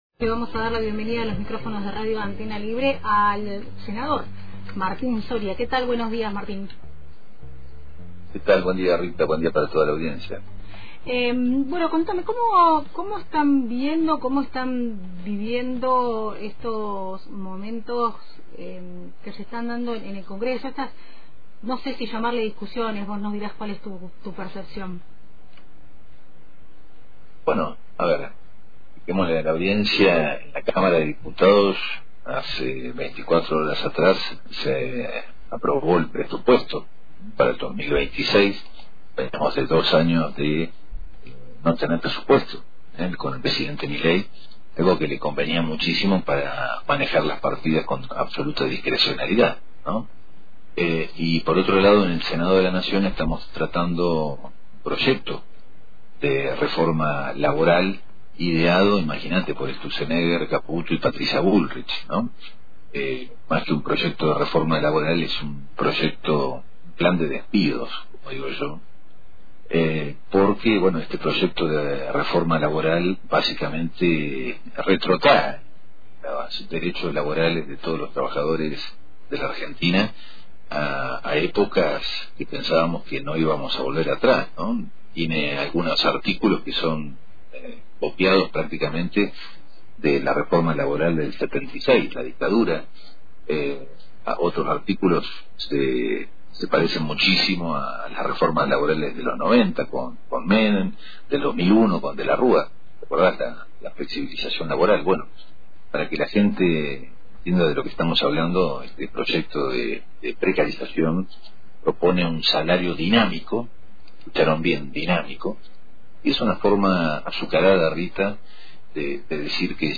El senador Nacional por Río Negro, Martín Soria, se refirió al momento político que atraviesa el Congreso tras la aprobación del presupuesto y al tratamiento de iniciativas clave que generan un fuerte debate a nivel nacional. Durante la entrevista, el legislador compartió su lectura sobre el rol del Parlamento, el uso de las herramientas institucionales y el impacto de las decisiones que se están tomando en el plano económico y social. Además, Soria dejó definiciones críticas sobre el modelo impulsado por el gobierno nacional, trazó comparaciones con etapas anteriores de la historia argentina.